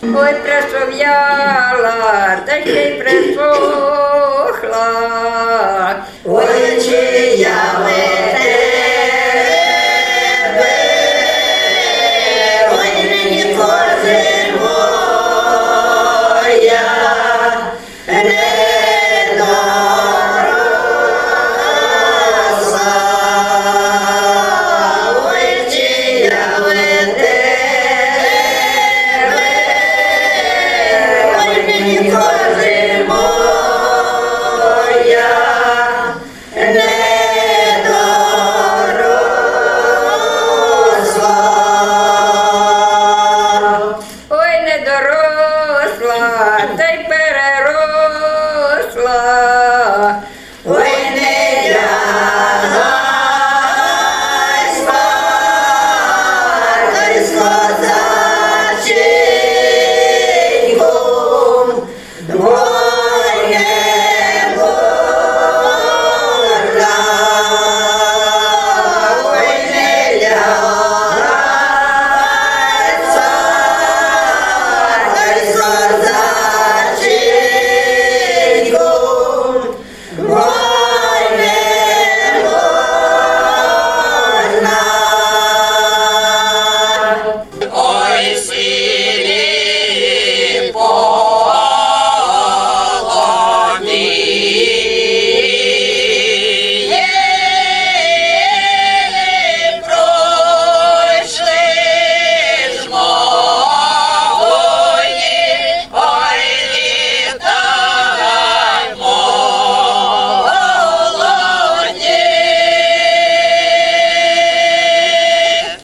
Фрагмент козацького співу